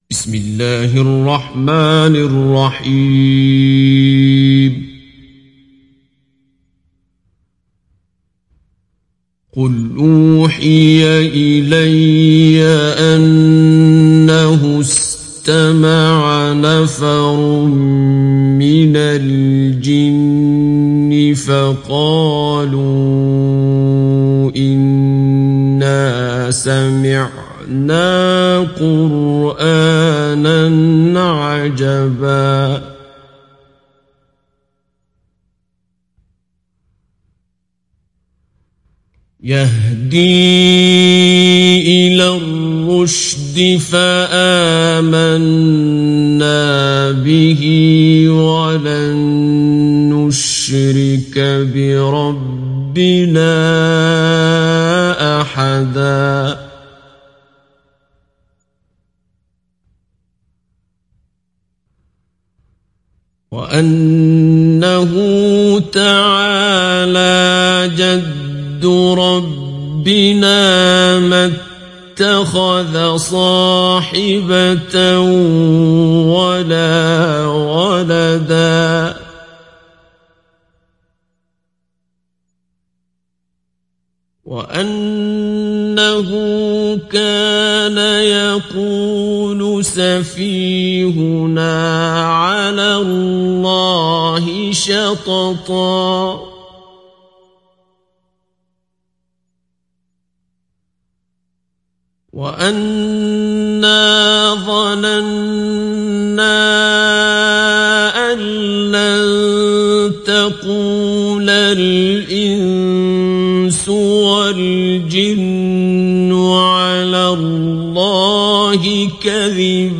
ডাউনলোড সূরা আল-জিন্ন Abdul Basit Abd Alsamad Mujawwad